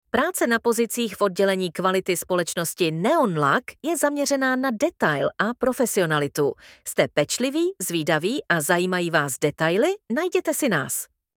Práce v oddělení kvality (audiospot)